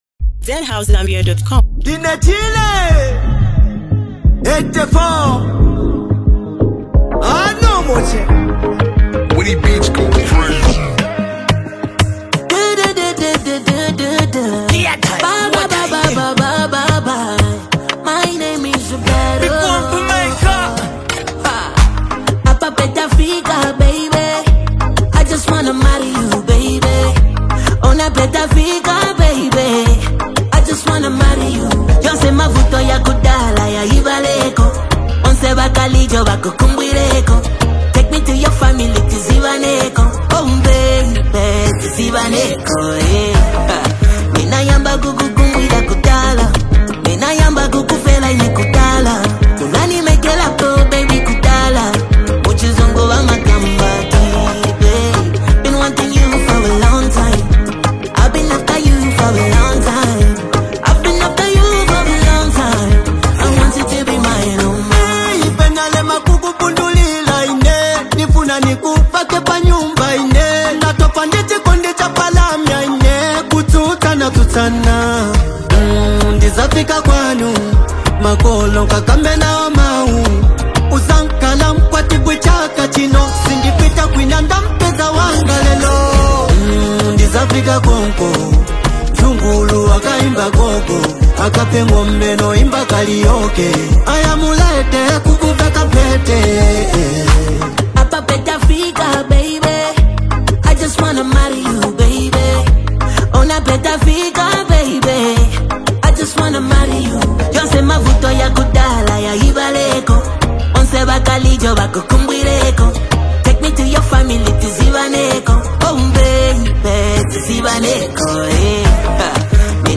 a sweet fusion of love, melody, and African rhythm.